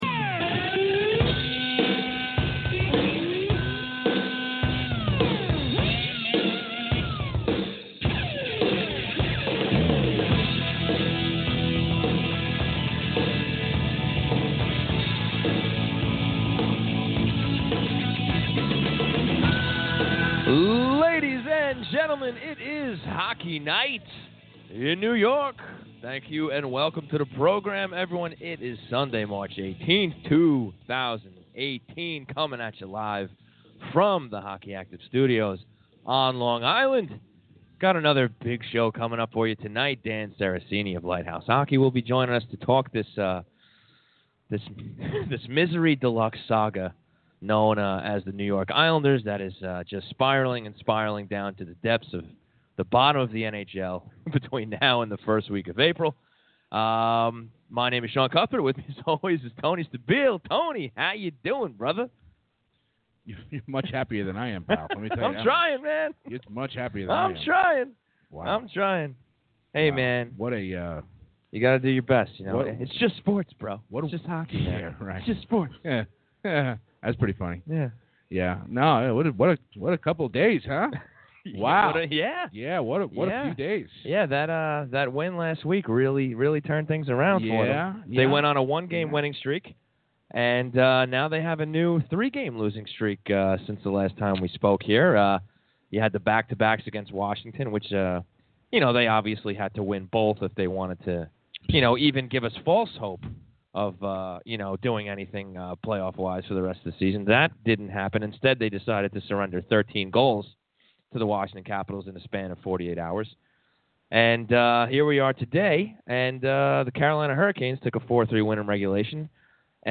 Weekly Featured Interviews